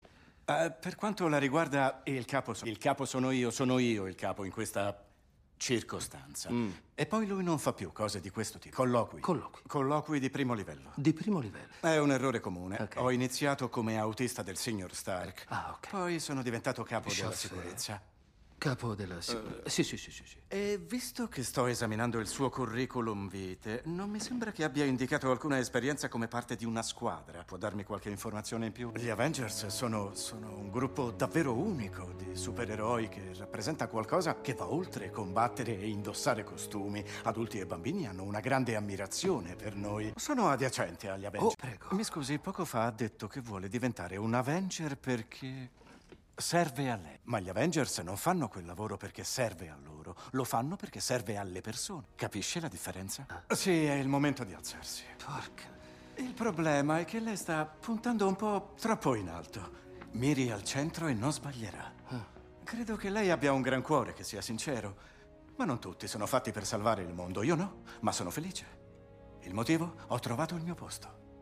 IL MONDO DEI DOPPIATORI
Il mondo dei doppiatori
FILM CINEMA